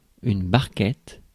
Ääntäminen
Synonyymit barquerolle cagette Ääntäminen France: IPA: [baʁ.kɛt] Haettu sana löytyi näillä lähdekielillä: ranska Käännöksiä ei löytynyt valitulle kohdekielelle.